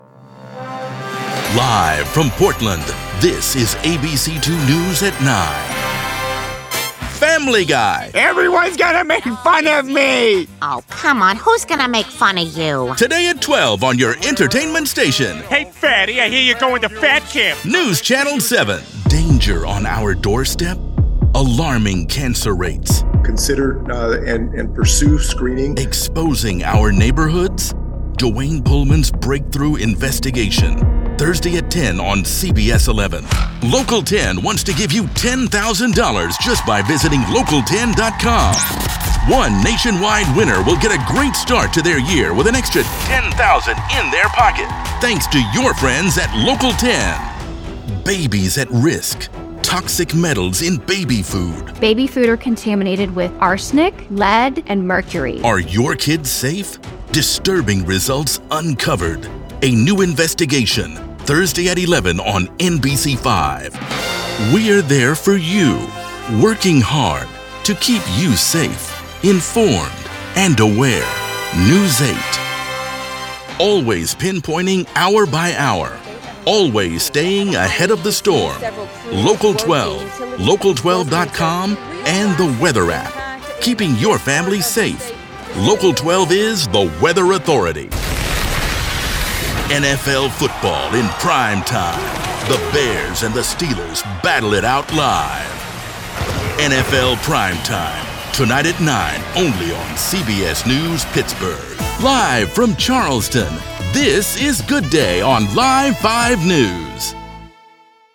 TV Affiliate Demo
English - USA and Canada
Middle Aged